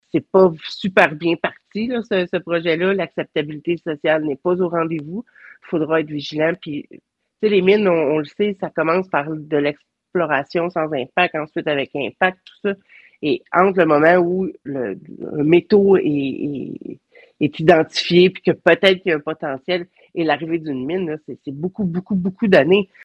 Entrevue à la radio CFNJ du préfet de la MRC Matawinie, Mme Isabelle Perreault, au sujet de la rencontre du 1er décembre à St-Côme - 29 novembre 2024